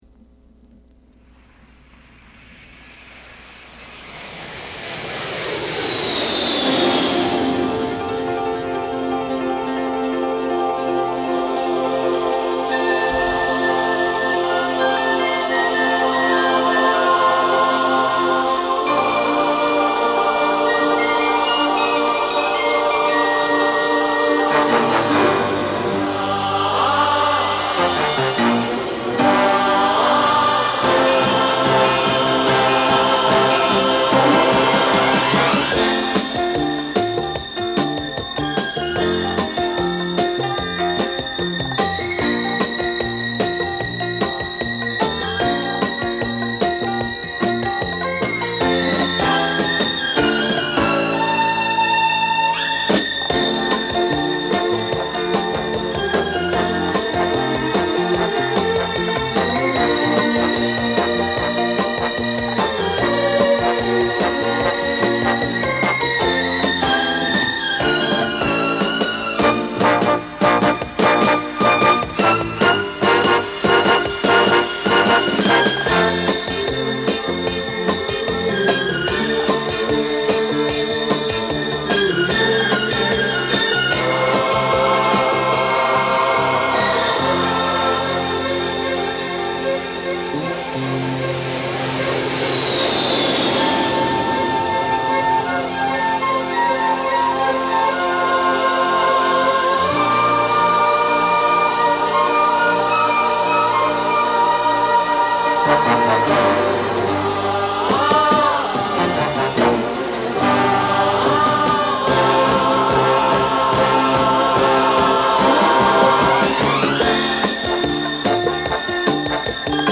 Genre: Easy Listening / Orchestra Pop
パリのパテ・マルコニEMIスタジオにて録音。